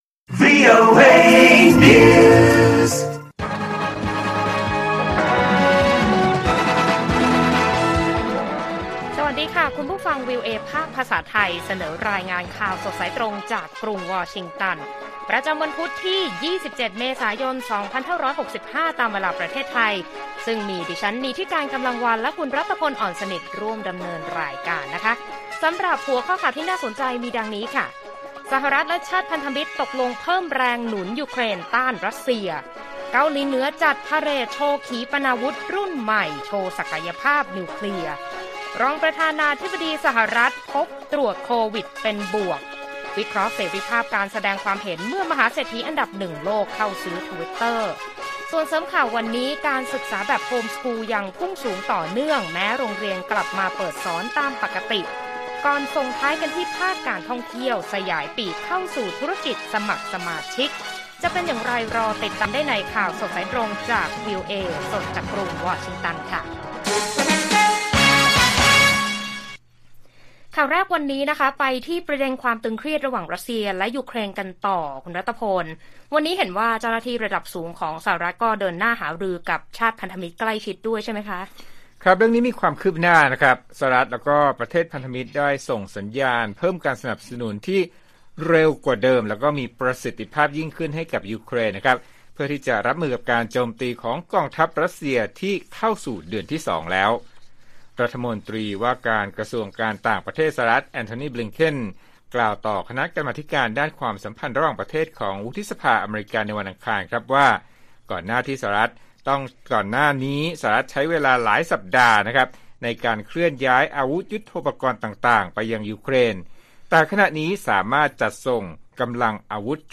ข่าวสดสายตรงจากวีโอเอไทย 6:30 – 7:00 น. วันที่ 27 เม.ย. 65